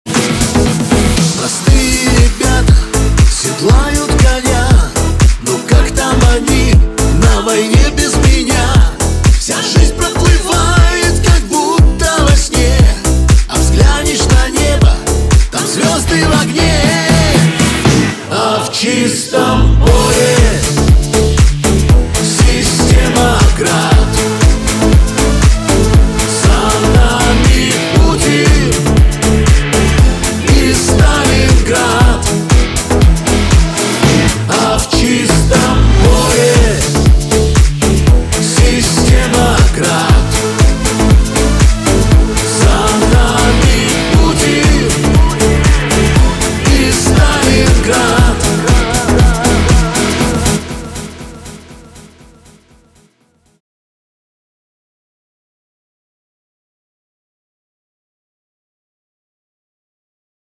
• Качество: 320, Stereo
мужской голос
патриотические
цикличные